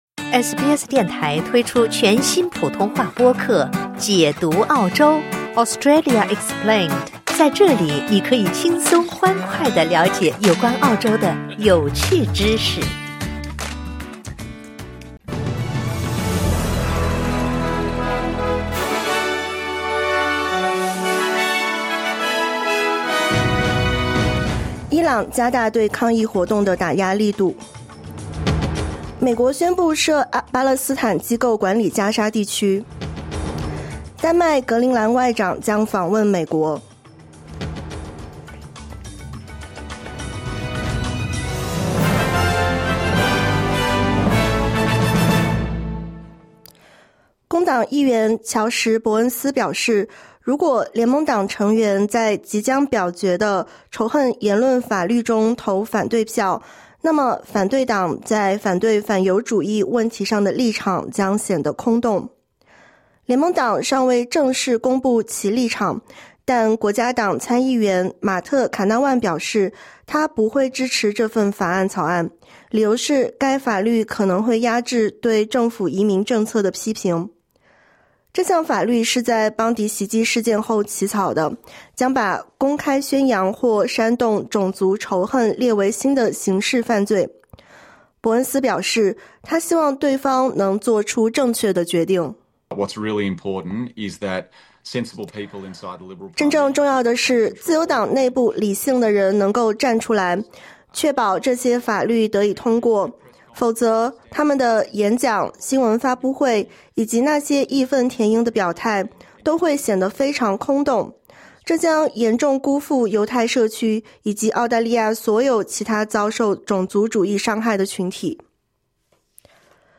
【SBS早新闻】伊朗加大对抗议活动的打压力度